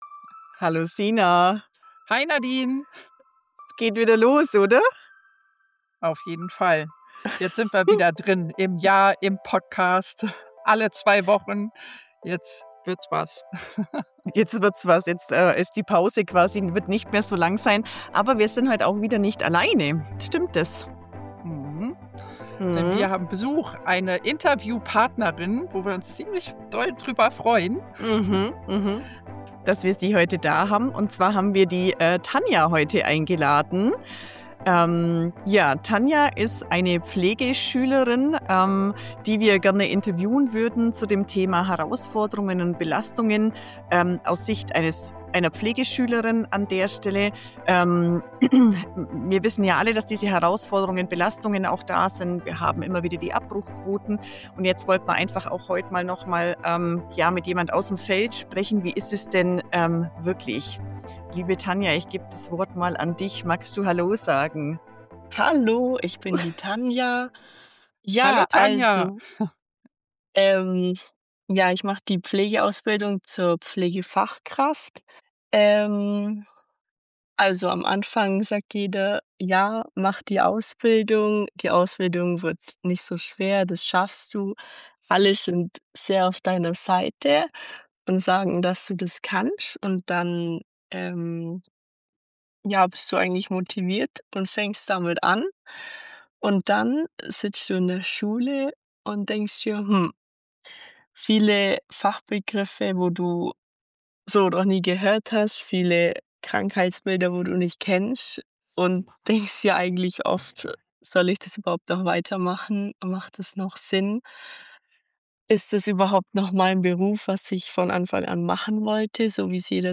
Die Interviewpartnerin wurde aus Datenschutzgründen im Podcast verfremdet.